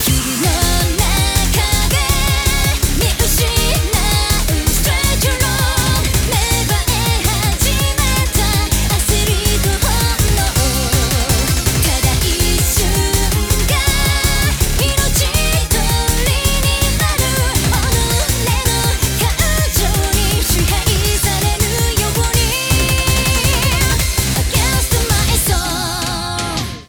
新レースBGM追加！